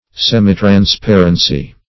Semitransparency \Sem`i*trans*par"en*cy\, n. Imperfect or partial transparency.
semitransparency.mp3